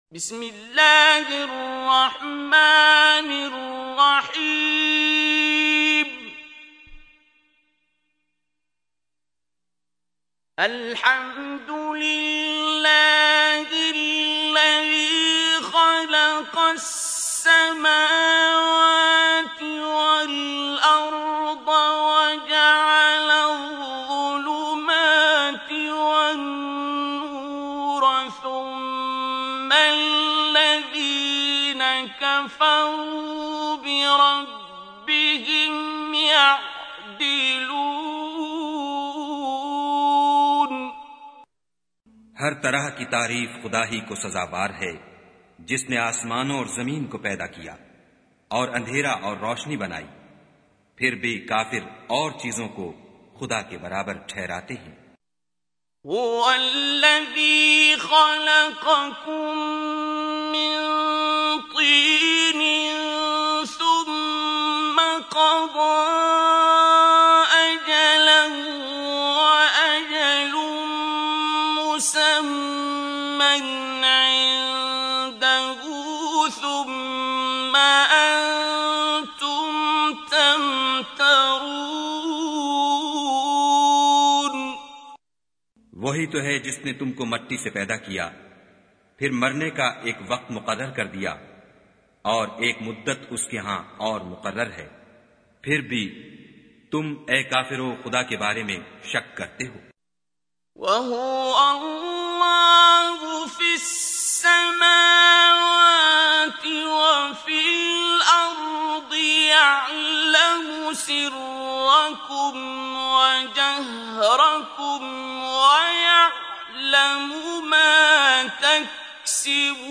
Listen online and download beautiful urdu translation of Surah Al Anaam recited by Qari Abdul Basit.